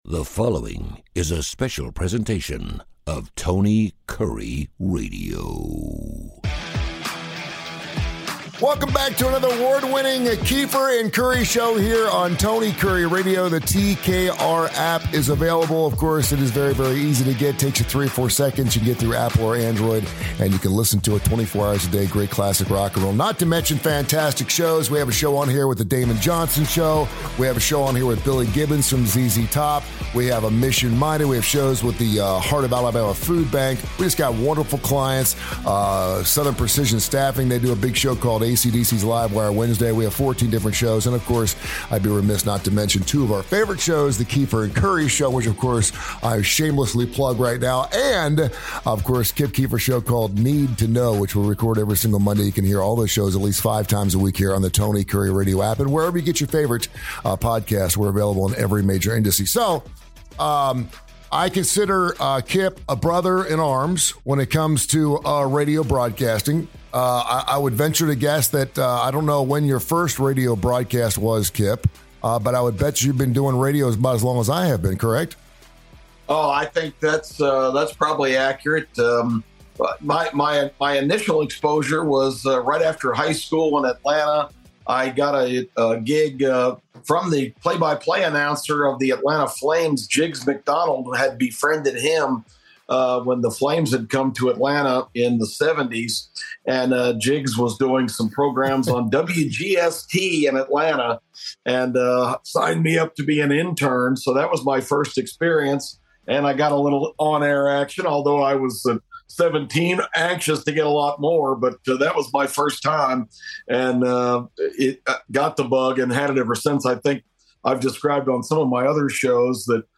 Two guys who have loved radio for over 100 years.